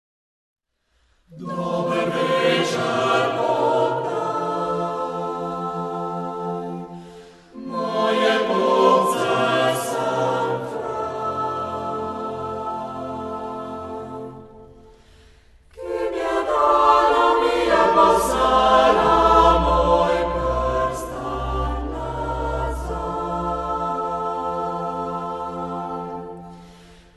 Mešani pevski zbor Mačkolje deluje v istoimenski vasi v bližini Trsta neprekinjeno že celih 60 let.